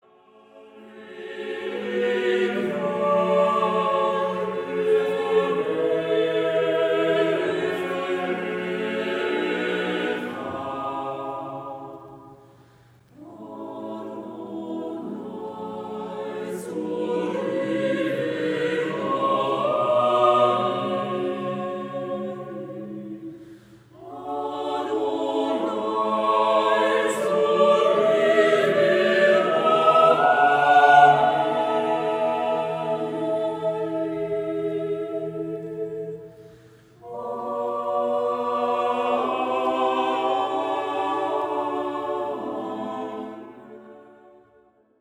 Cantorial and Choral masterpieces
recorded in Berlin